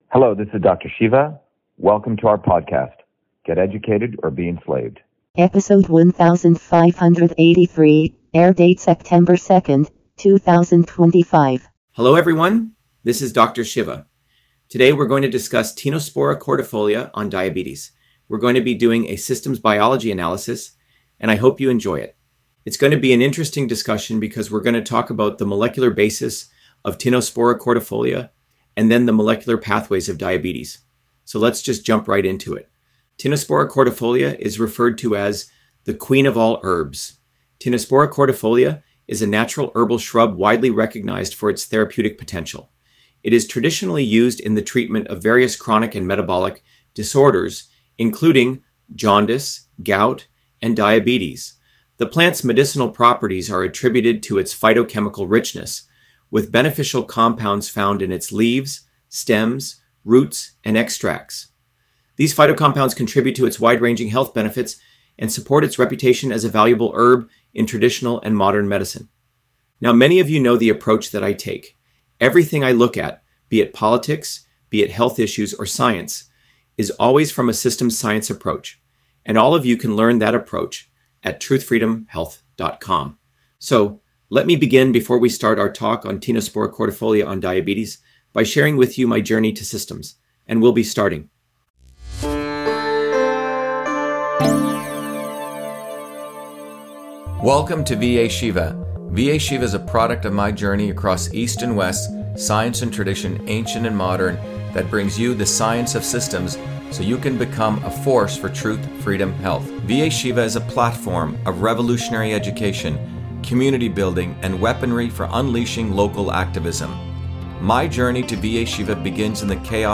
In this interview, Dr.SHIVA Ayyadurai, MIT PhD, Inventor of Email, Scientist, Engineer and Candidate for President, Talks about Tinospora cordifolia on Diabetes: A Whole Systems Approach